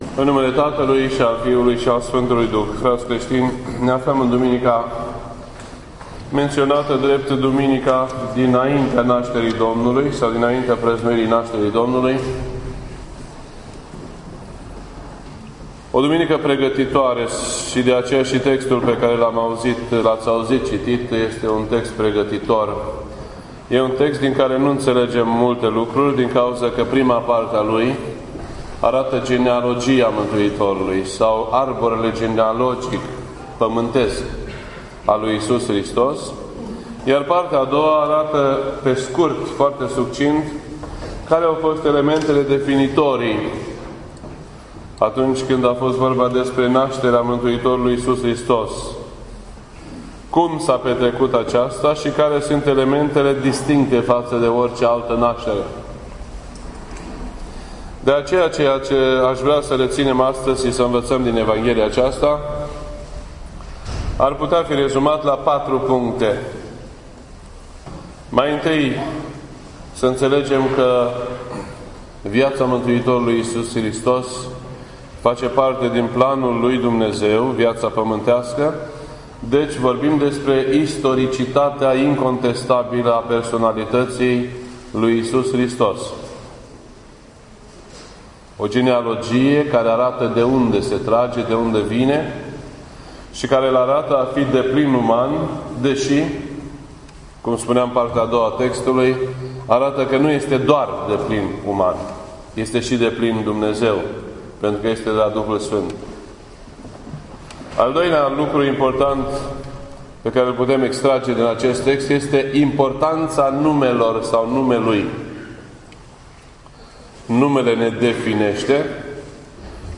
This entry was posted on Sunday, December 20th, 2015 at 11:32 AM and is filed under Predici ortodoxe in format audio.